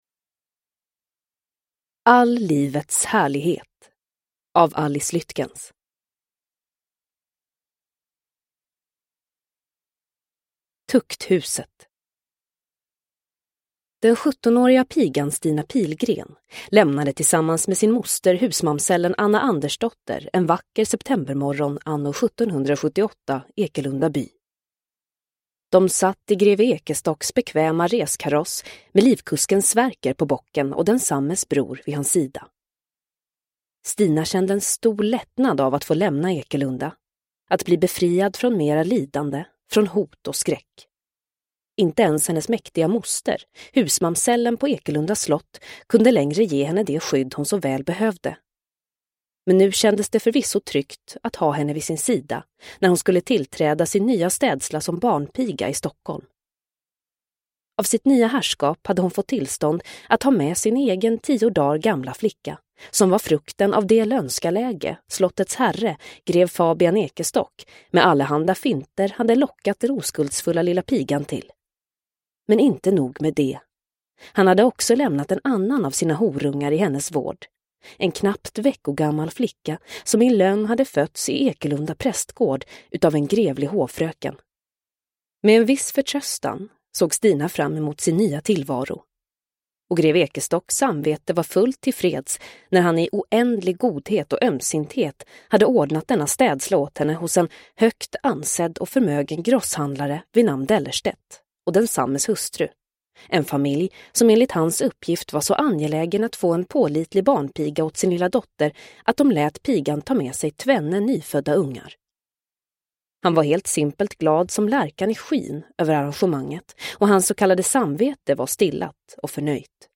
All livets härlighet – Ljudbok – Laddas ner